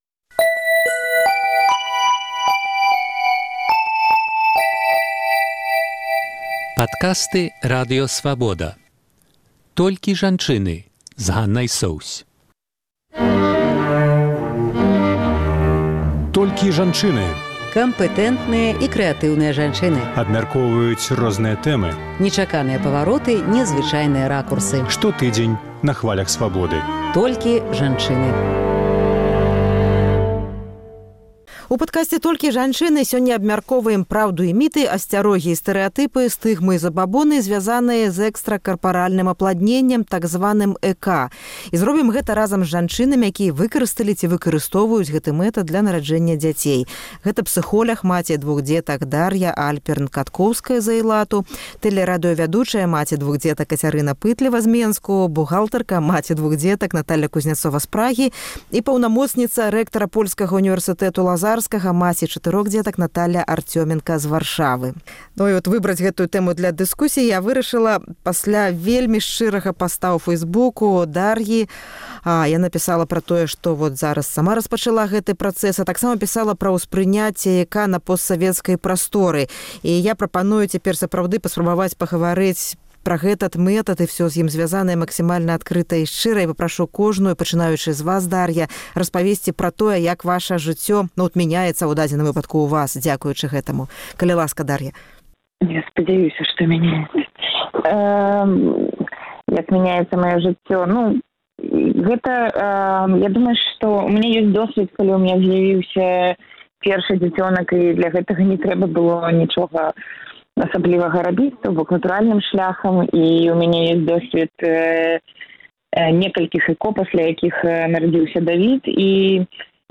Абмяркоўваем праўду і міты, асьцярогі і стэрэатыпы, стыгмы і забабоны, зьвязаныя з экстра карпаральным апладненьнем,так званым ЭКА, разам з жанчынамі, якія выкарысталі ці выкарыстоўваюць гэты мэтад дзеля нараджэньня дзяцей.